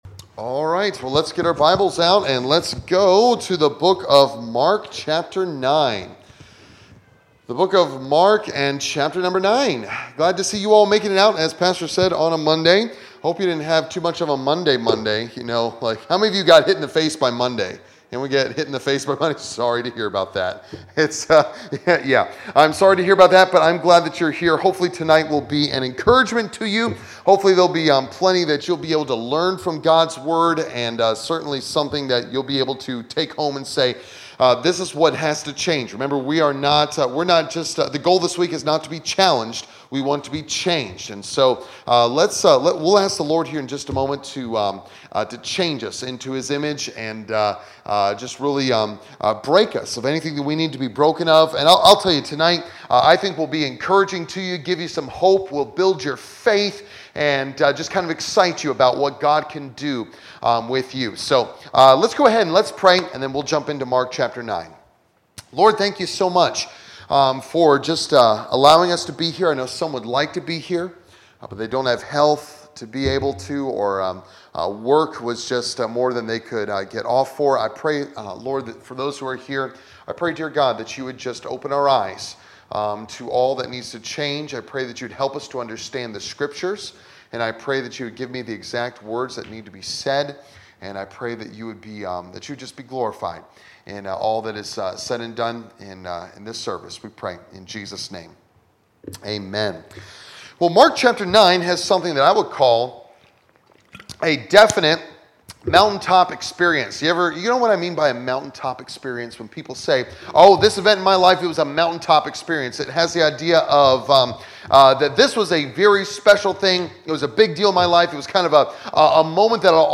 Series: Revival Meeting Passage: Mark 9